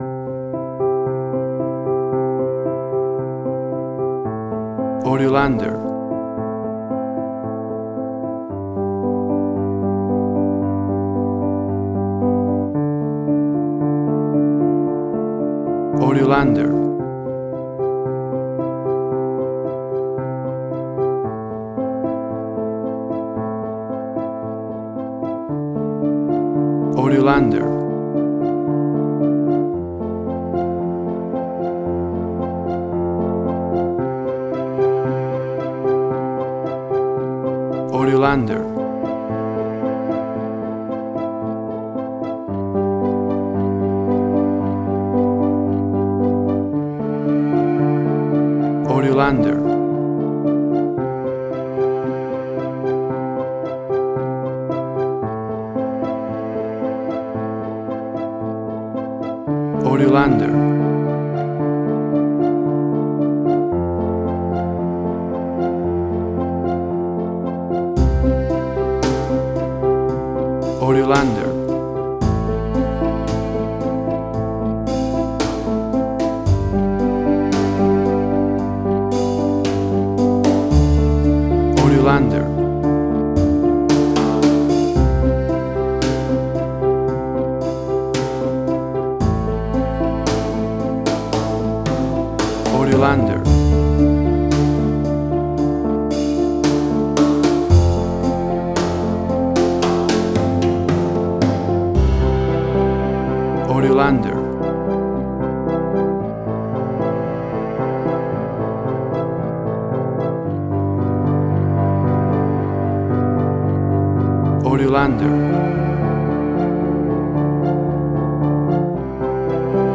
Suspense, Drama, Quirky, Emotional.
Tempo (BPM): 56